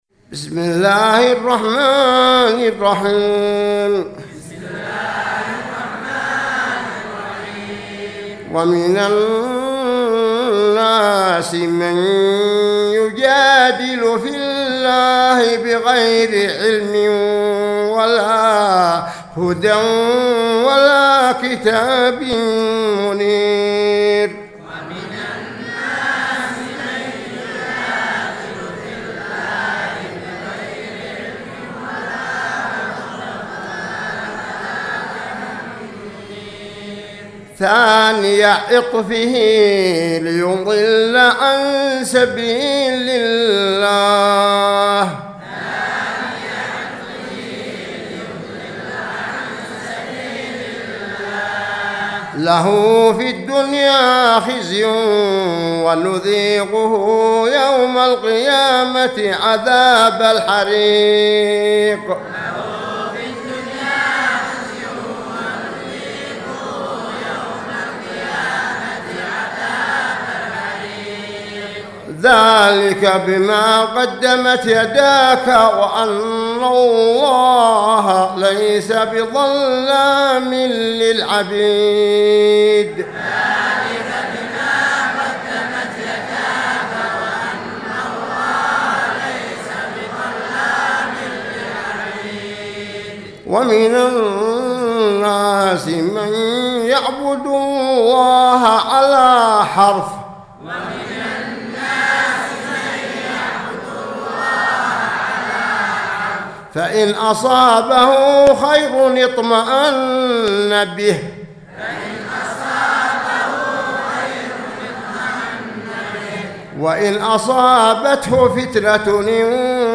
سورة الحج مع الترديد من 8 إلى 16